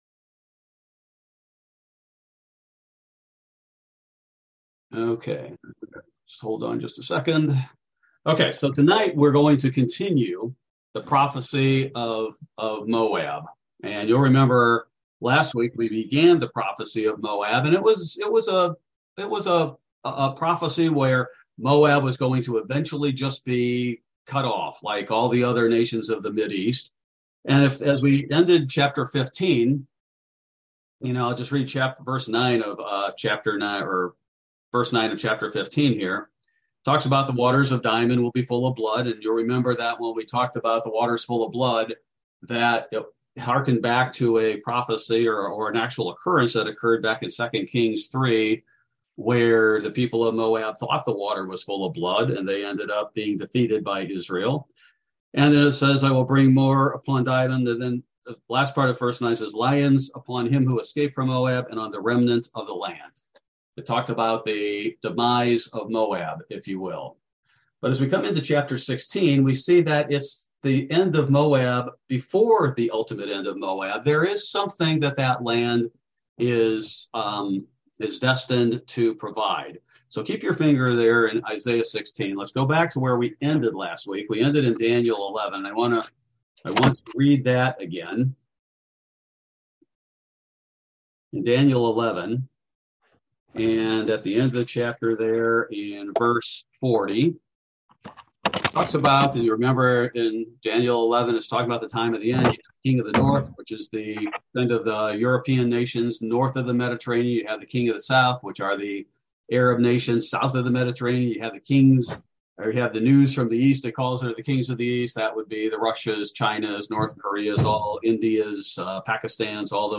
Bible Study: November 16, 2022